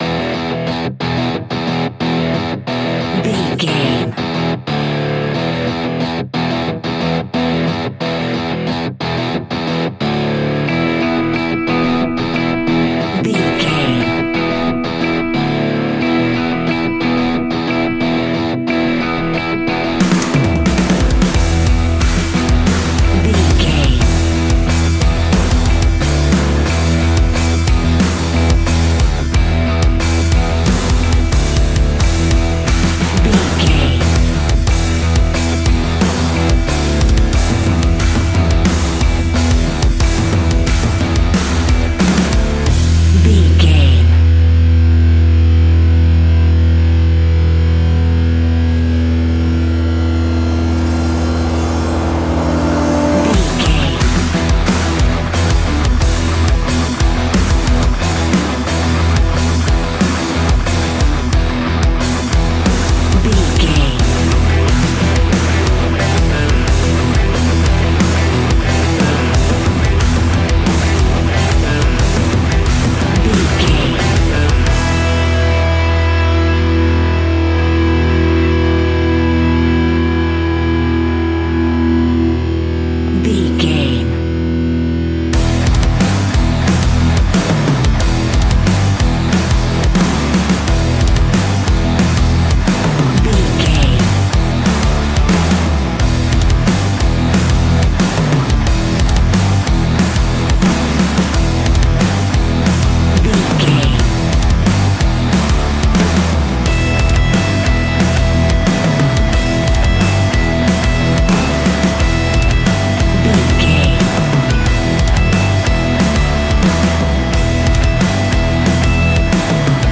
Ionian/Major
E♭
hard rock